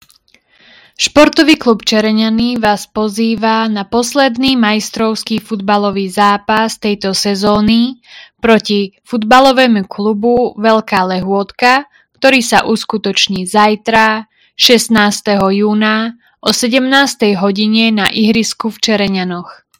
Hlásenie obecného rozhlasu – Futbal 14.06.2025 o 17:00